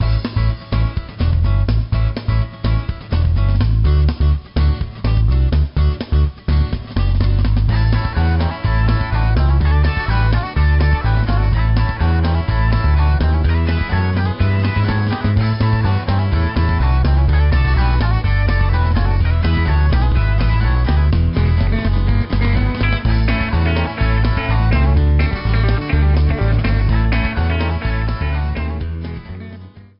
The main theme music
Edited Cut at 30s, fadeout of 3s at the end.